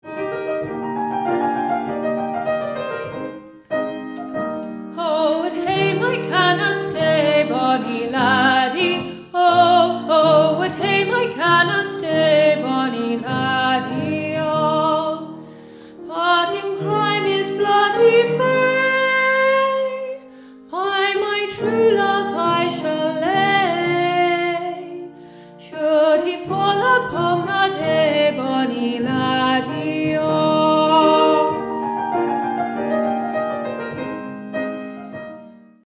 Many thanks to all the fabulous local singers for entertaining us so wonderfully at Walworth’s History Through Song 2023 at St Peter’s Church in July.